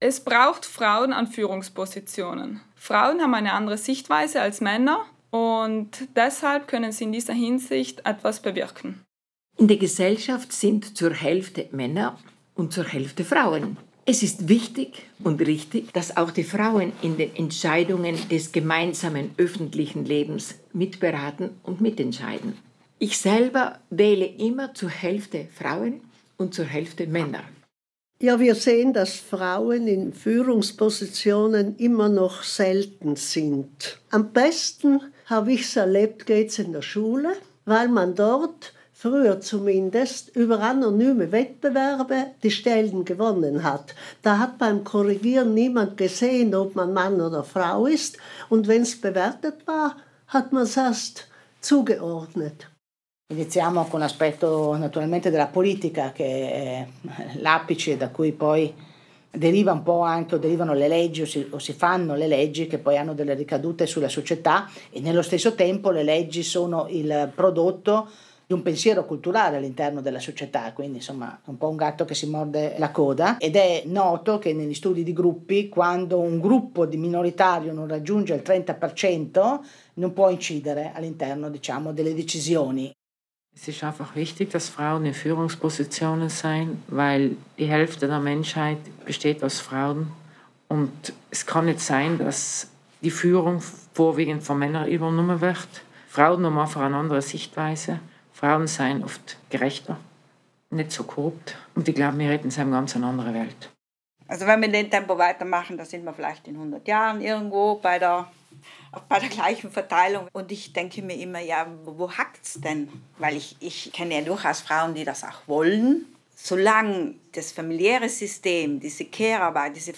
Dreißig Frauen erzählen und reflektieren in einem vielstimmigen Chor über ihre Arbeit und die Ziele, für die sie gekämpft haben und weiterhin kämpfen.